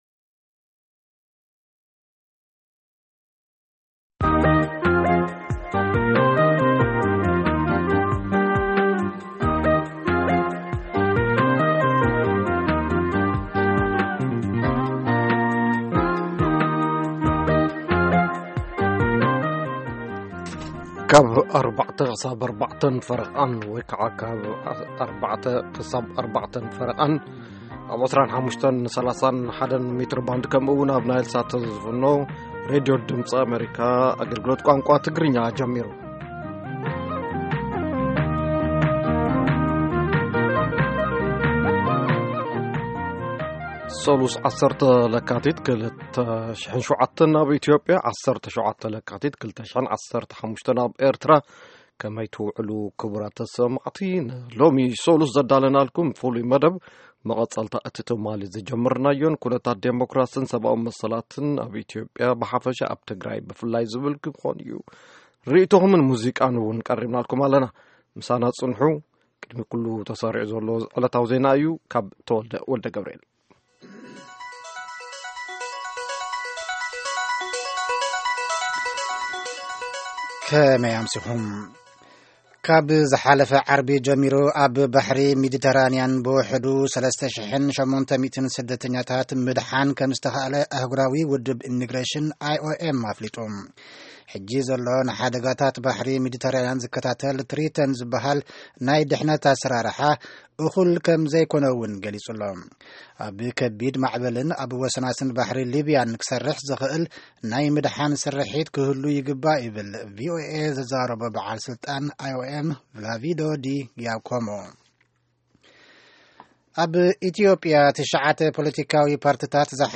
Tigrigna News